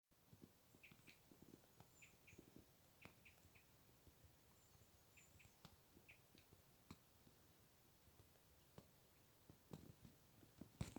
Birds -> Finches ->
Crossbill Loxia sp., Loxia sp.
StatusVoice, calls heard